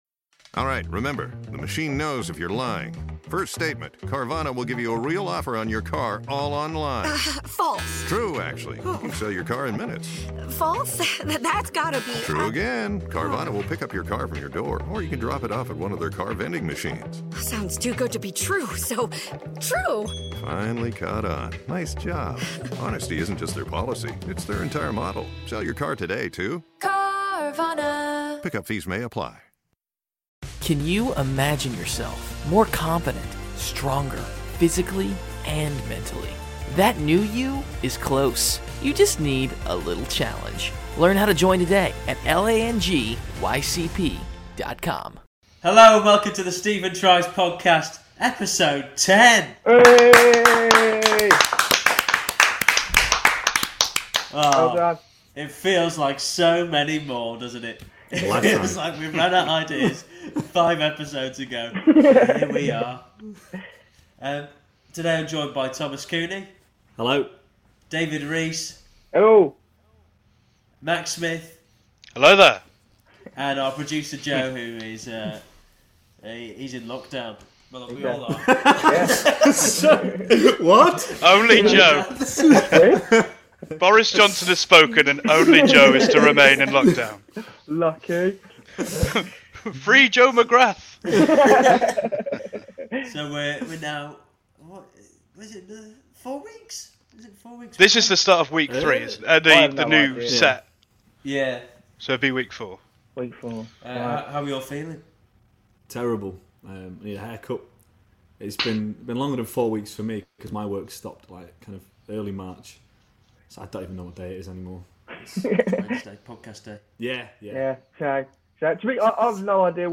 This week 4 athletes discuss all things sports. From Sports Day, to losing a game of badminton to a (very talented) dwarf.